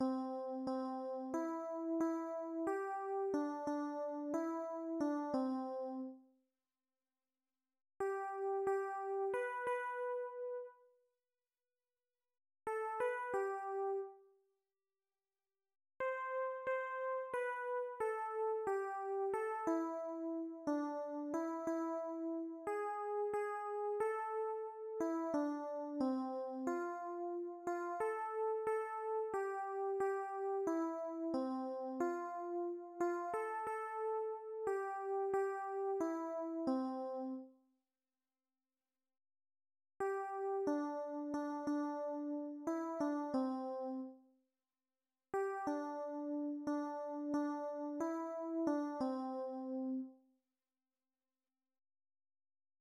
Műfaj magyar könnyűzenei dal